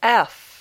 /ɛf/